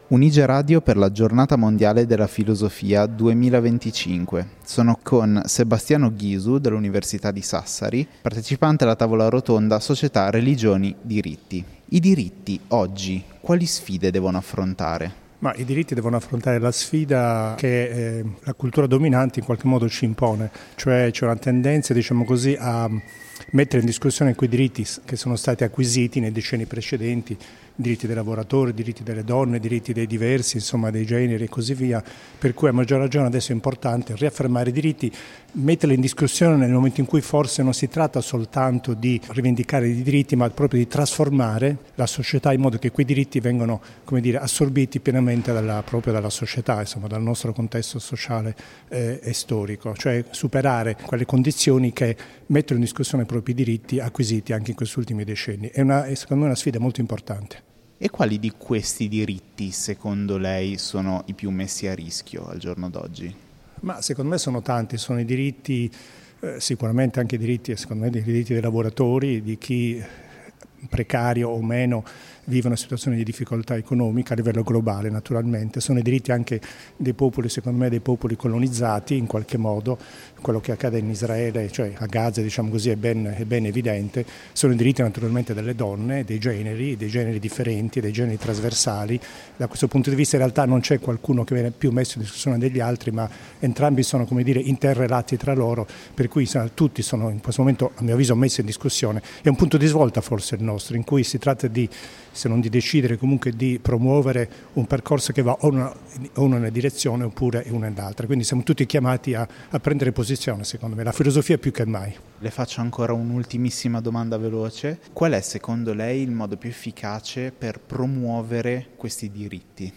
Un’intervista per riflettere su diritti, azione e trasformazione sociale.